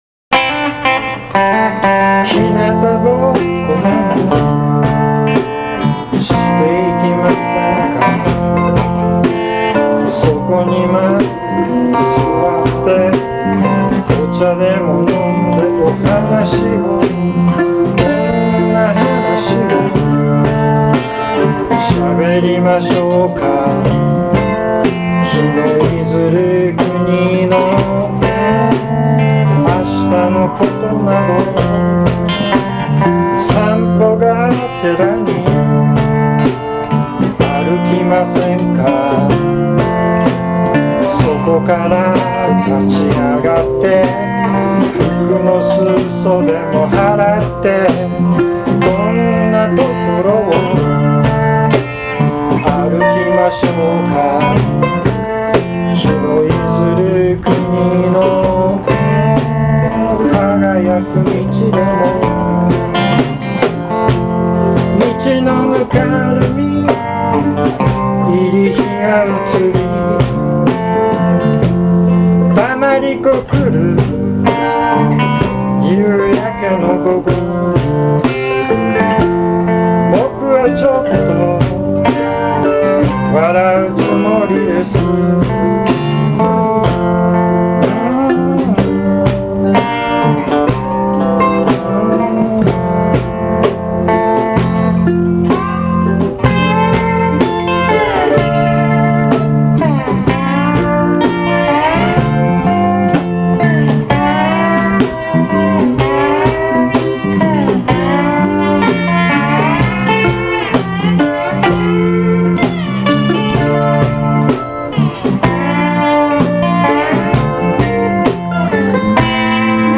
ペダルスティール・リックスを弾きます。
リハーサル・テイクのMP3ファイルの